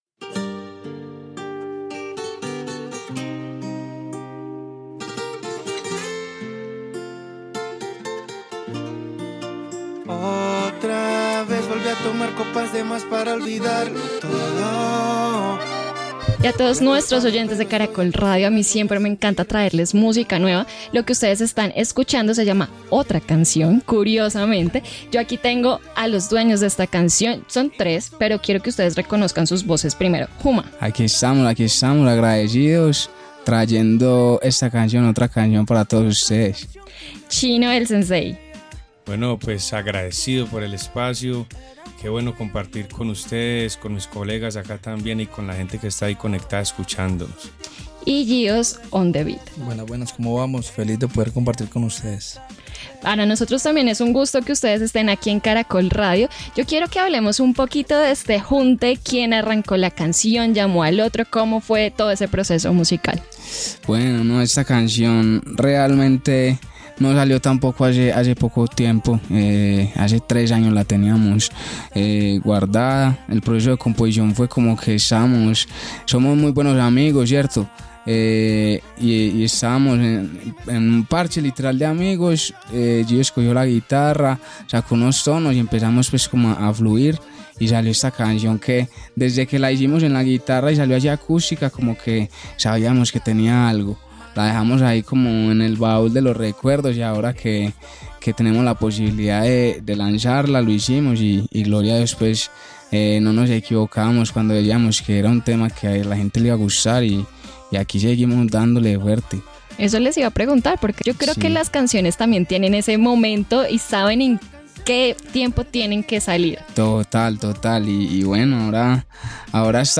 una propuesta fresca y sentimental que fusiona la música popular y el reggaetón con una historia de amor